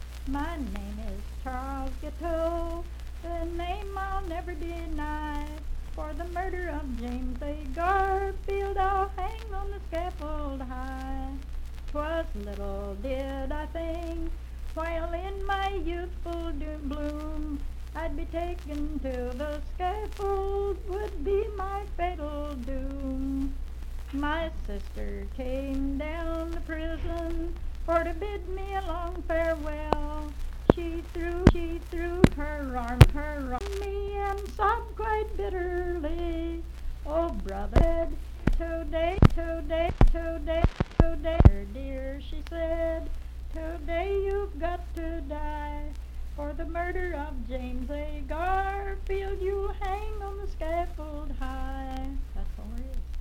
Unaccompanied vocal music
Voice (sung)
Moorefield (W. Va.), Hardy County (W. Va.)